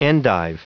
Prononciation du mot endive en anglais (fichier audio)
Prononciation du mot : endive